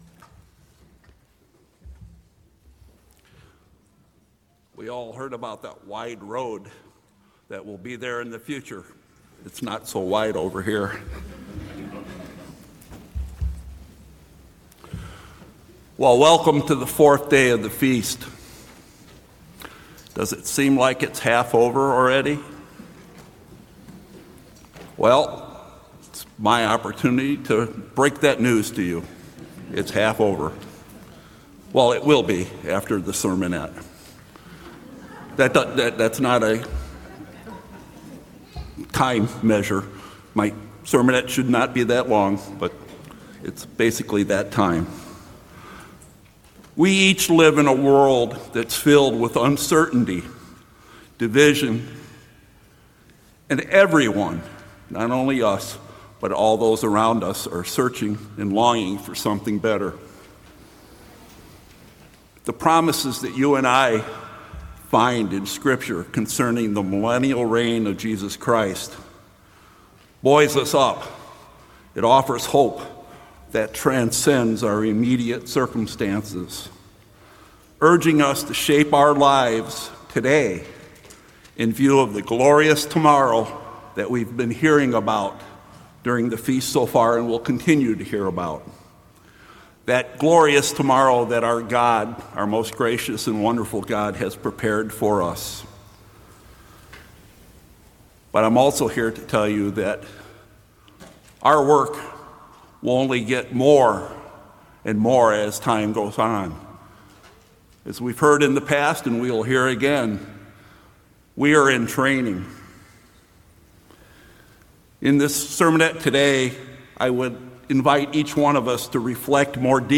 Sermons
Given in Lake Geneva, Wisconsin 2025